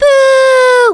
Princess Peach taunts the player.